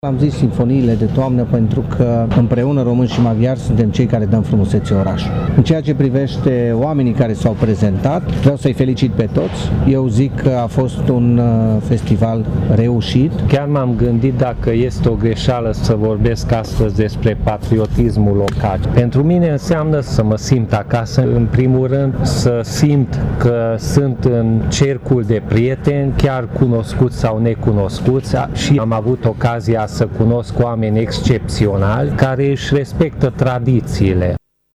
Viceprimarii Tîrgu-Mureșuiui, Claudiu Maior și Peti Andras au subliniat că românii și maghiarii dau împreună frumusețe orașului, respectiv faptul că patriotismul local nu e o vorbă goală.